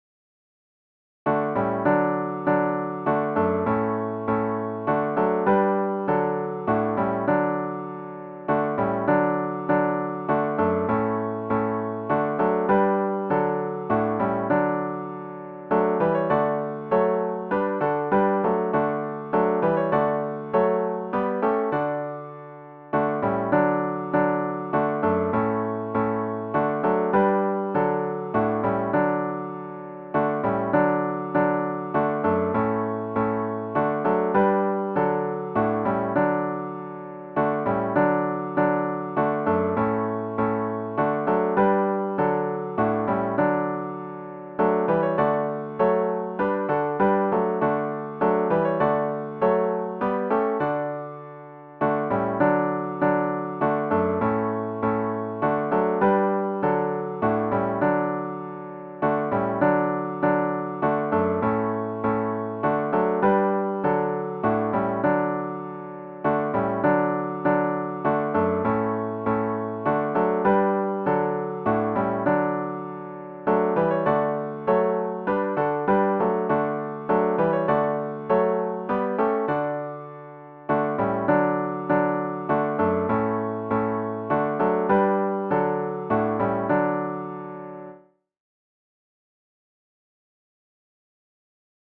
*CLOSING HYMN “Come, Thou Fount of Every Blessing” GtG 475